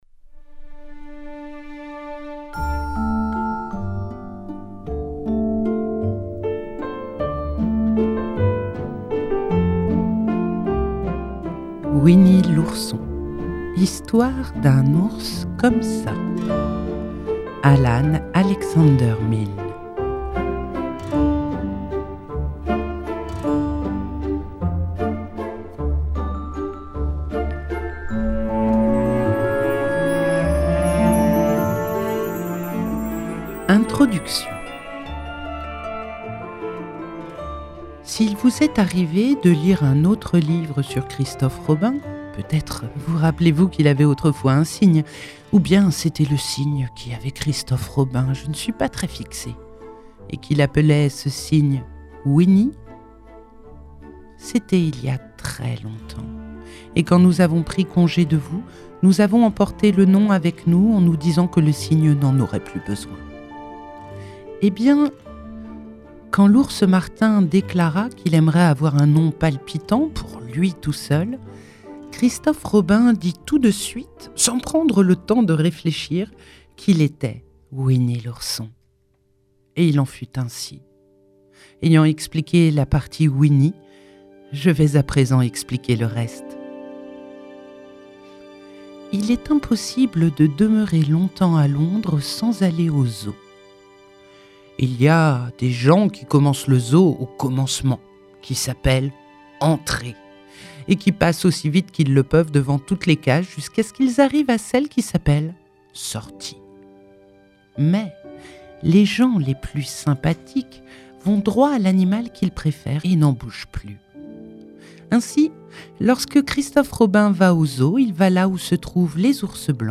🎧 Winnie l’Ourson – Alan Alexander Milne - Radiobook